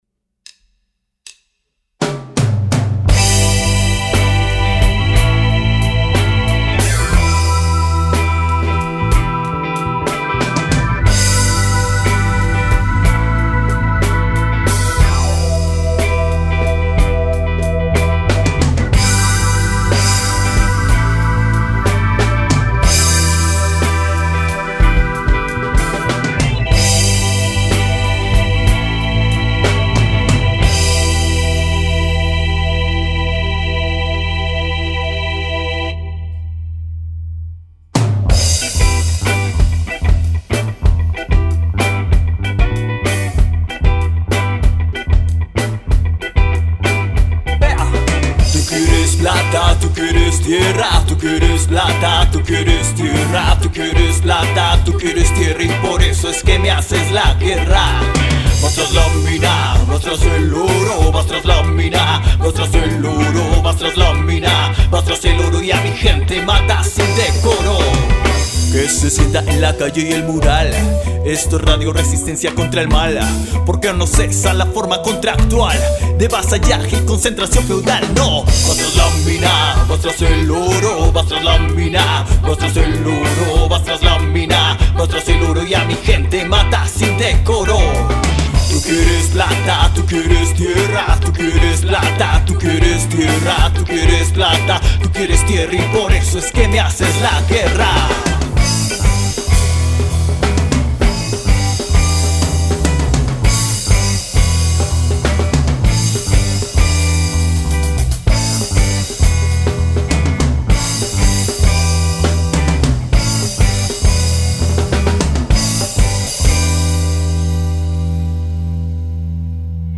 Canción
voz.
guitarra.
bajo eléctrico.
batería.
teclados.
Flauta traversa.
Coros.